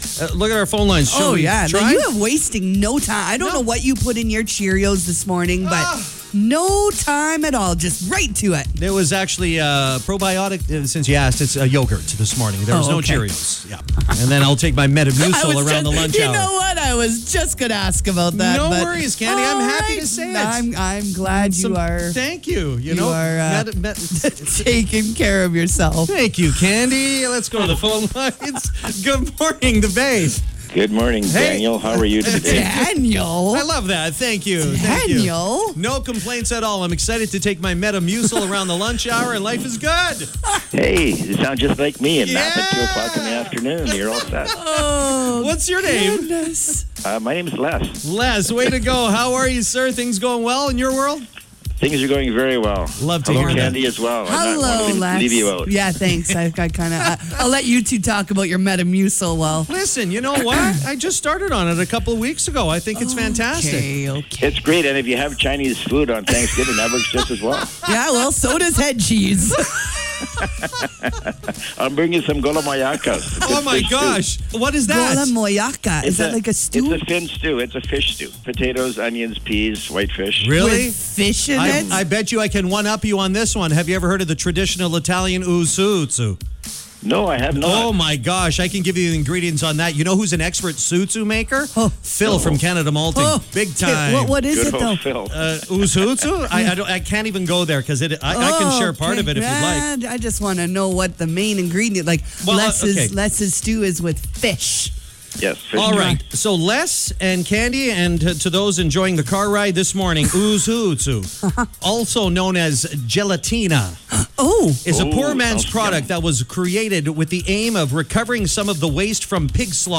Sometimes a conversation can take a different turn. That’s exactly what happened this morning on MORNINGS IN THE BAY.